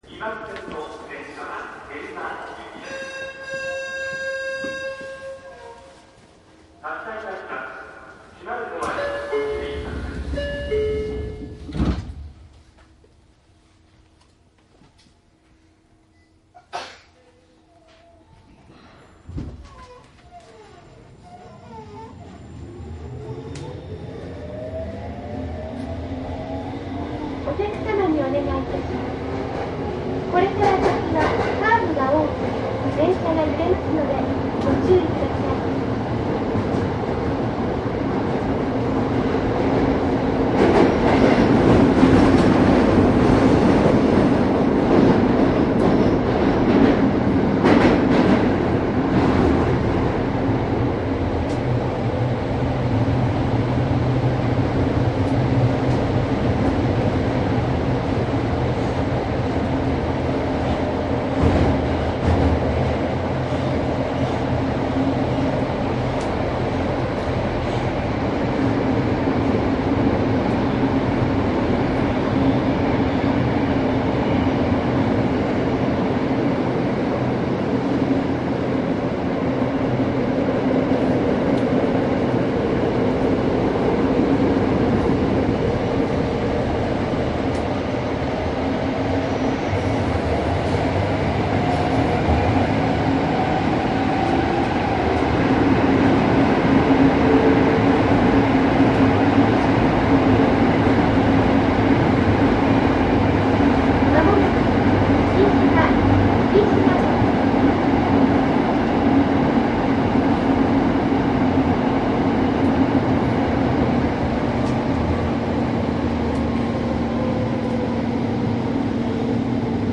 西武有楽町線 07系 走行音CD
いずれもマイクECM959です。DATかMDの通常SPモードで録音。
実際に乗客が居る車内で録音しています。貸切ではありませんので乗客の会話やが全くないわけではありません。